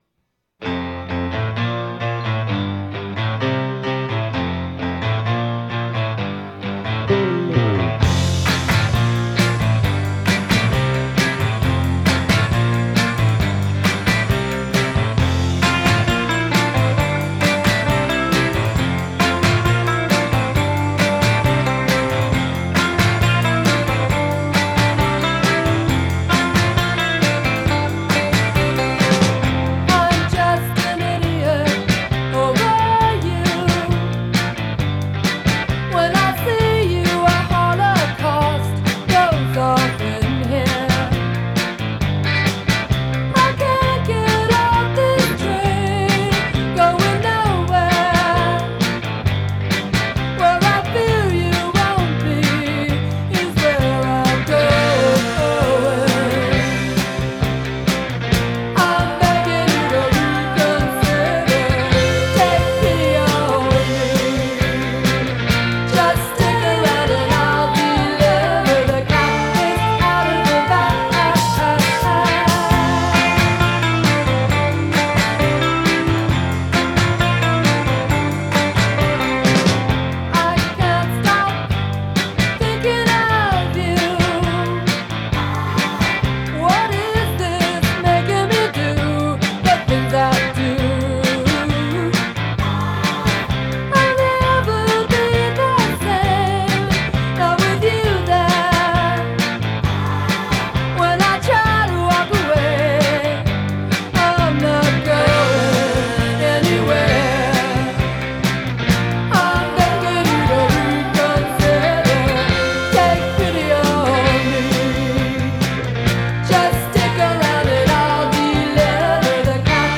the latter featuring killer handclaps and jangly lead guitar